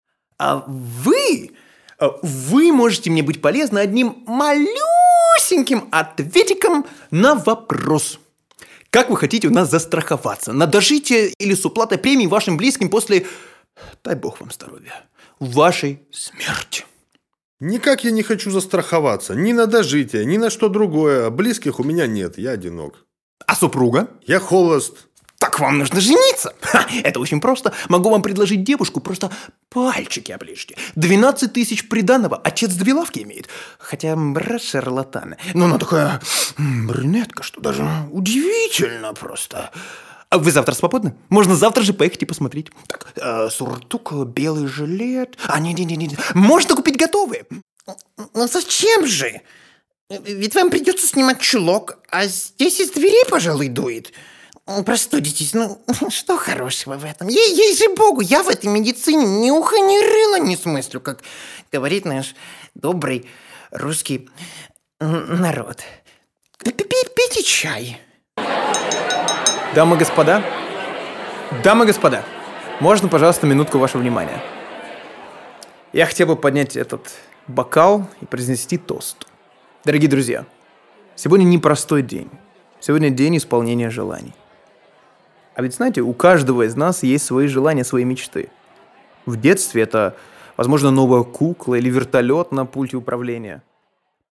Professional voice and theatre actor.
Sprechprobe: Sonstiges (Muttersprache):
Russian Characters 3 MB.mp3